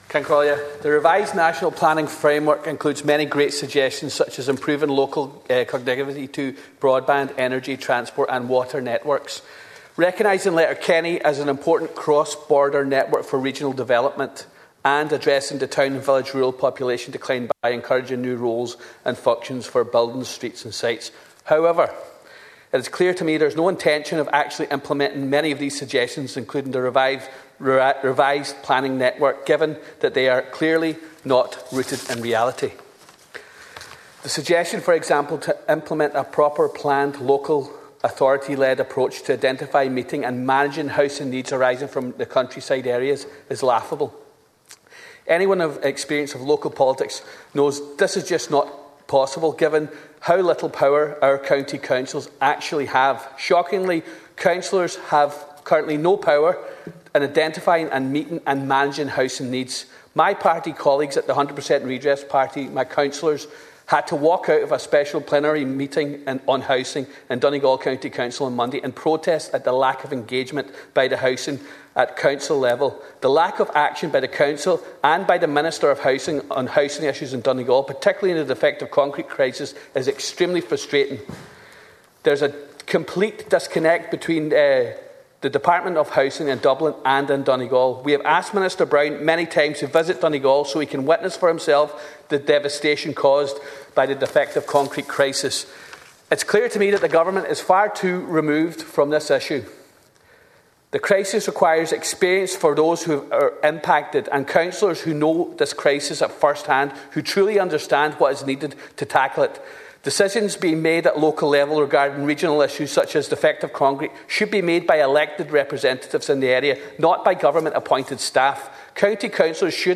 The Dáil chamber heard that County Councils need to be given more power to address local issues, particularly those surrounding housing.
Donegal Deputy Charles Ward told the house of the walkout staged by his party colleagues during a special plenary meeting in County House Lifford earlier this week.